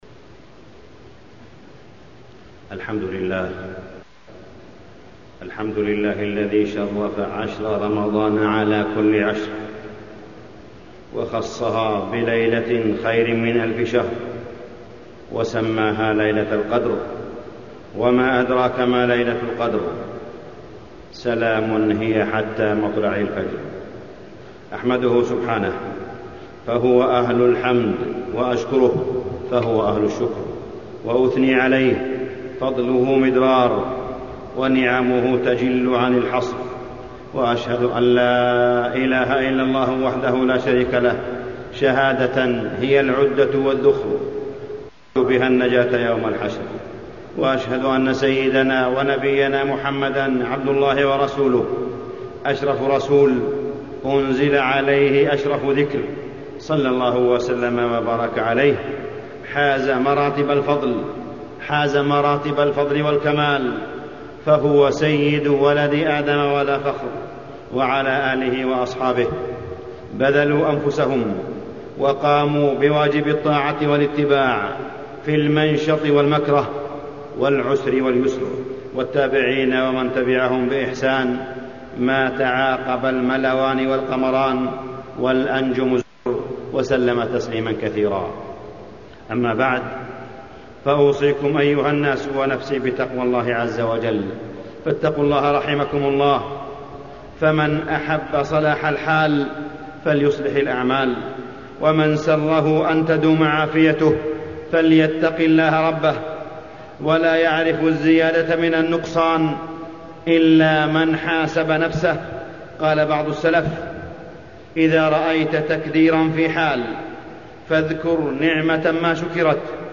موعظة العشر
تاريخ النشر ٢١ رمضان ١٤٢٧ هـ المكان: المسجد الحرام الشيخ: معالي الشيخ أ.د. صالح بن عبدالله بن حميد معالي الشيخ أ.د. صالح بن عبدالله بن حميد موعظة العشر